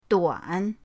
duan3.mp3